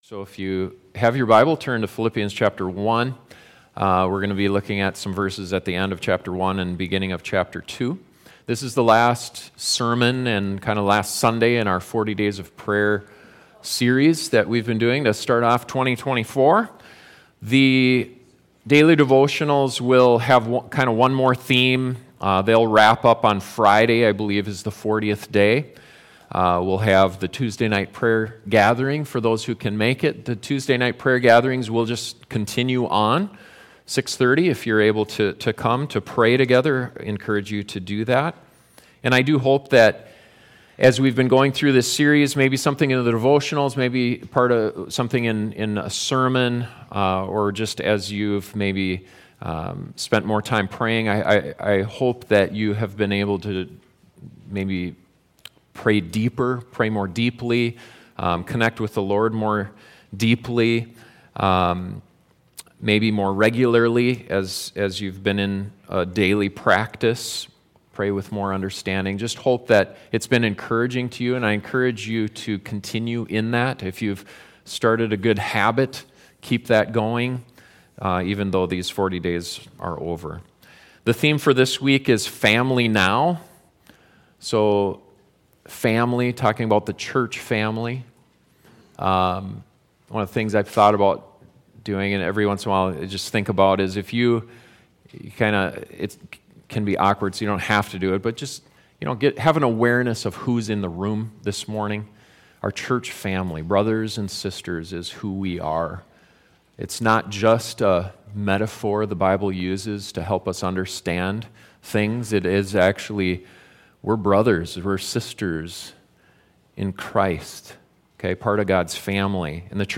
For our final sermon in the 40 Days of Prayer, we look at the importance of the church family and how we can stand firm together for the sake of […]